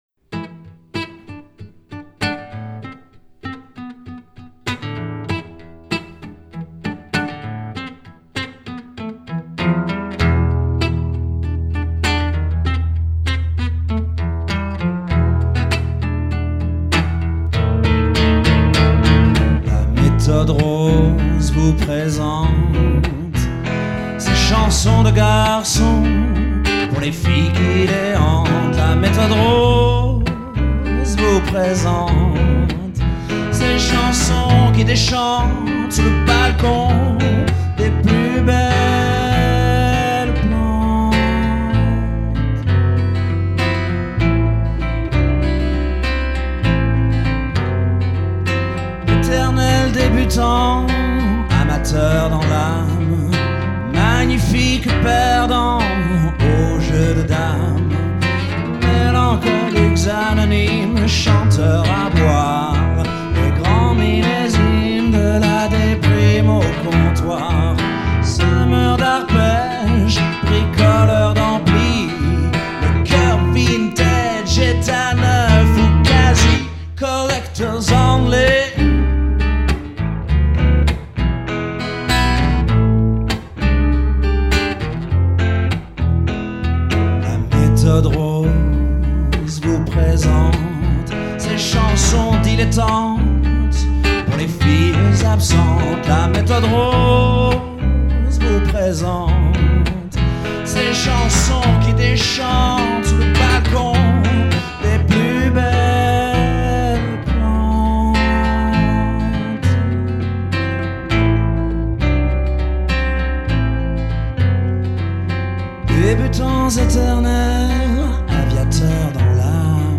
enregistré en 2005 à villagecool
guitare, chant
guitare
basse